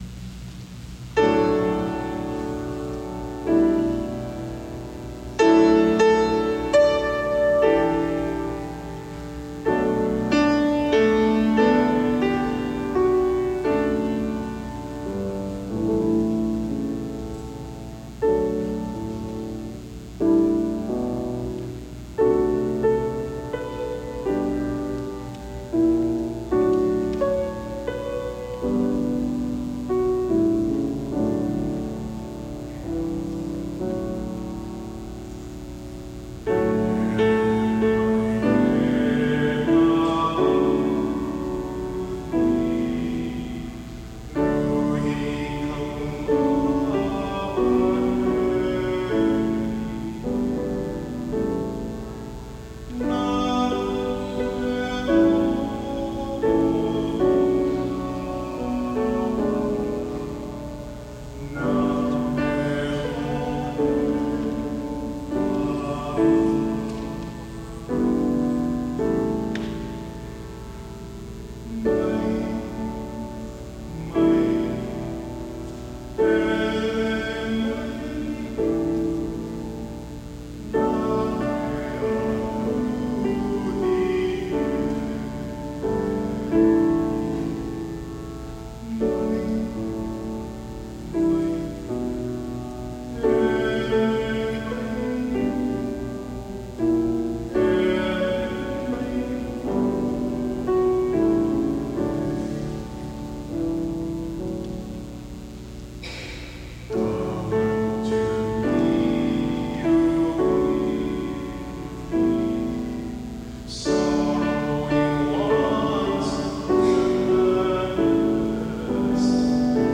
Enjoy an evening of Hawaiian-style church music by several Oahu parish choirs.
Hele Mai e Nä Luhi / I Ke Alo O Iesü  (Mondoy), (Kamamahi/Fukumoto) St. John Vianney Choir
This modern re-casting of the song hopes to mimic the same melodic charm of the old venerable hymn by using a modern musical vocabulary.